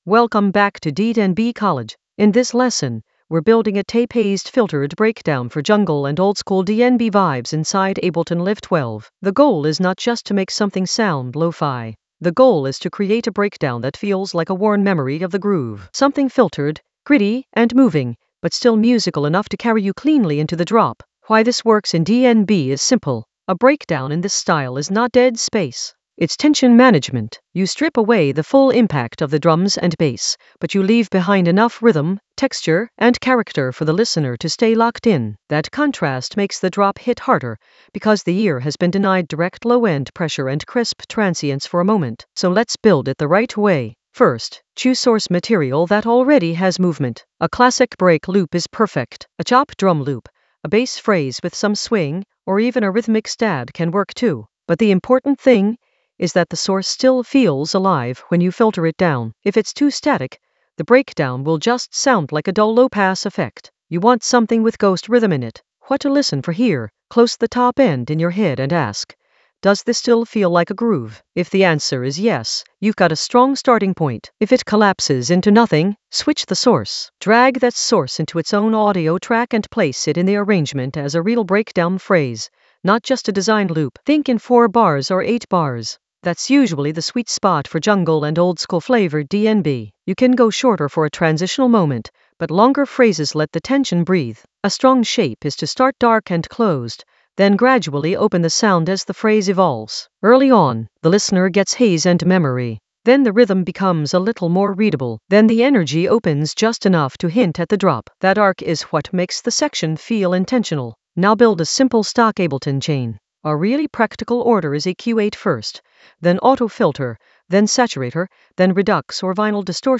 An AI-generated intermediate Ableton lesson focused on Tape Haze a filtered breakdown: design and arrange in Ableton Live 12 for jungle oldskool DnB vibes in the Mastering area of drum and bass production.
Narrated lesson audio
The voice track includes the tutorial plus extra teacher commentary.